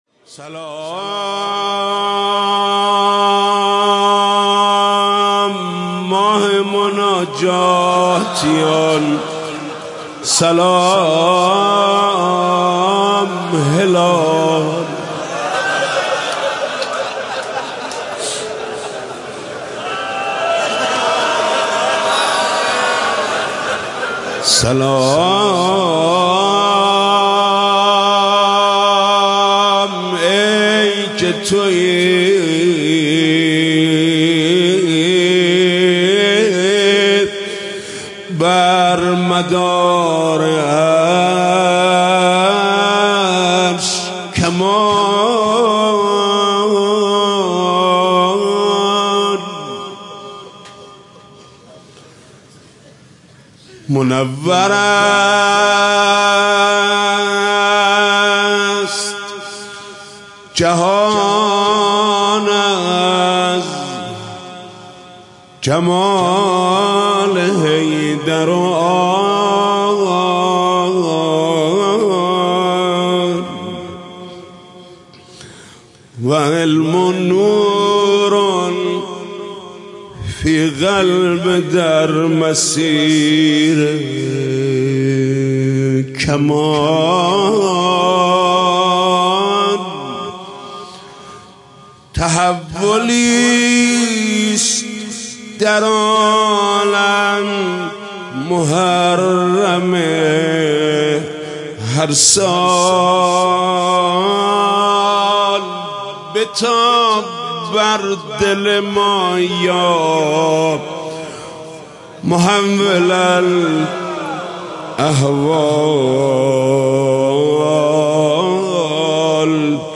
مداحی جدید حاج محمود کريمی شب اول محرم97 هيأت راية العباس